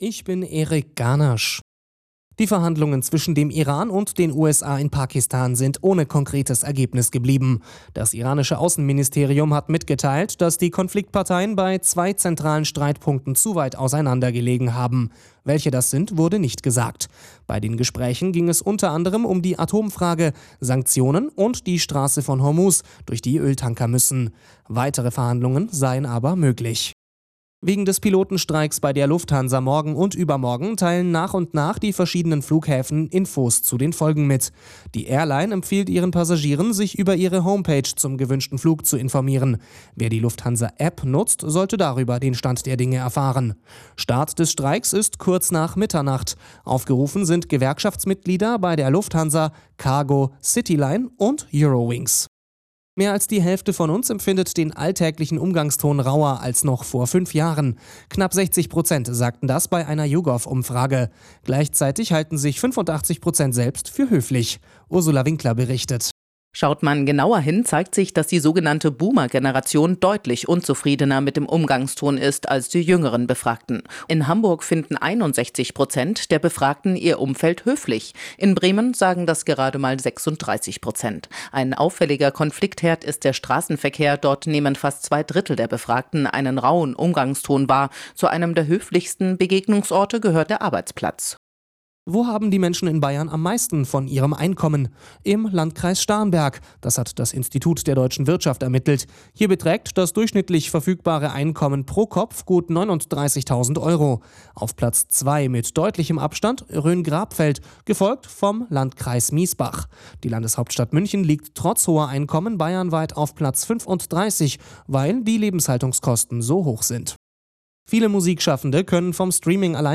Nachrichten , Nachrichten & Politik